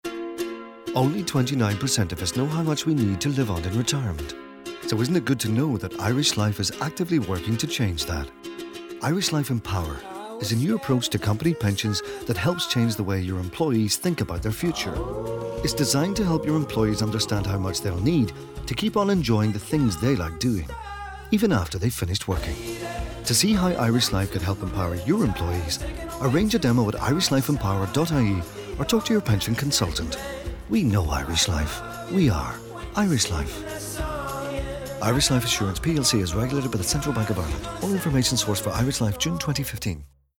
• Radio advertising on both Newstalk and Radio 1 running until mid November.